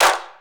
Waka SNARE ROLL PATTERN (55).wav